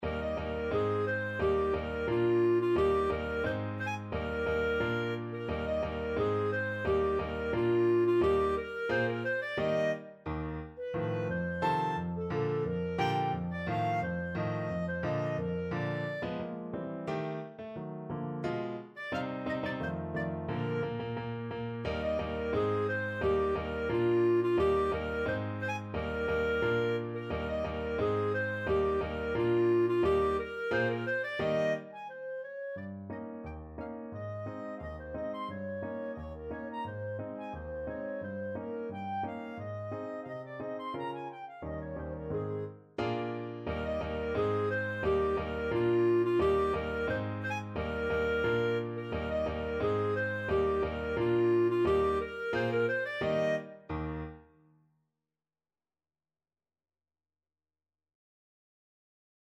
~ = 88 Stately =c.88
Classical (View more Classical Clarinet Music)